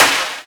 VEC3 Claps 088.wav